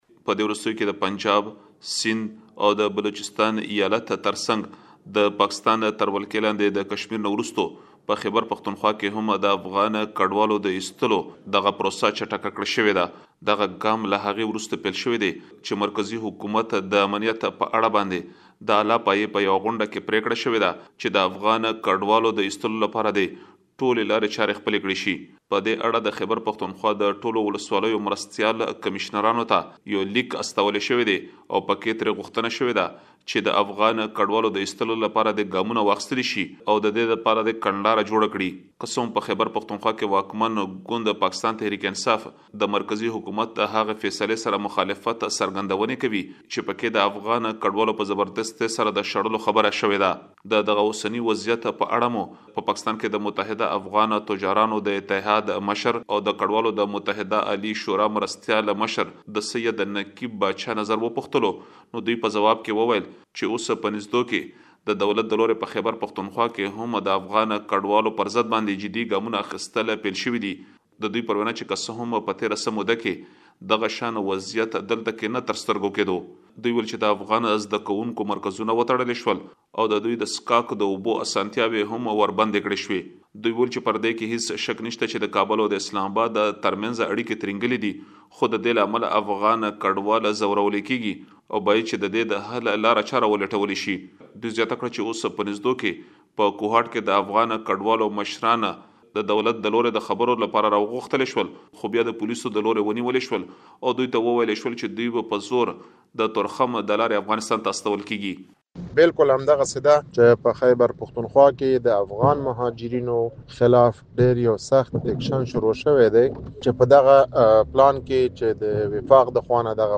په اسلام‌اباد کې د لوړې کچې په يوې غونډه کې پرېکړه شوې ده ترڅو د خیبر پښتونخوا له ټولو سیمو څخه افغان کډوال وویستل شي. په خیبر پښتونخوا کې د افغان کډوالو استازو د وروستیو اقداماتو په اړه اندېښنه ښوولې ده. مهرباني وکړئ په دې اړه لا ډېر معلومات په رپوټ کې واورئ.